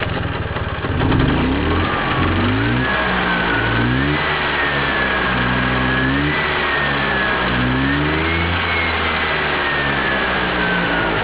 Vespa 125 Primavera 1973 Oben seht ihr ein paar Ansichtsphotos der Vespa. Wer einmal wissen will, wie sich der Sound der Vespa anhört, der sollte sich nicht scheuen und aufs Pic klicken.